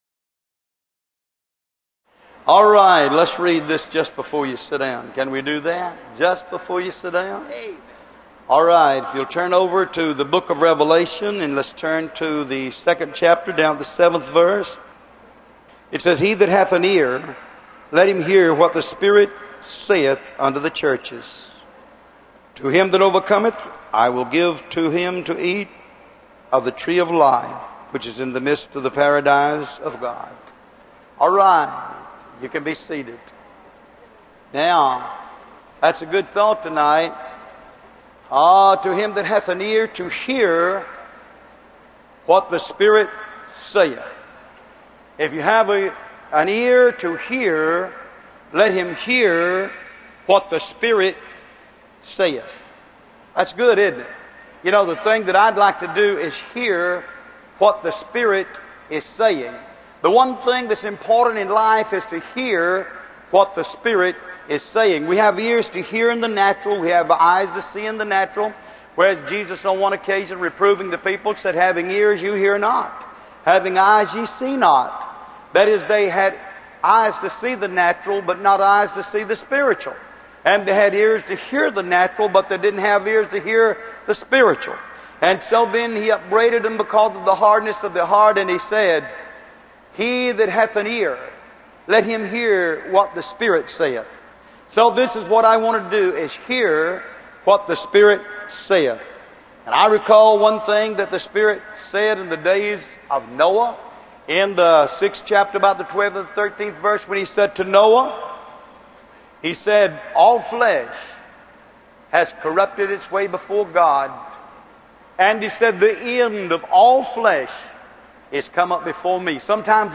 Location: King’s Mountain, NC USA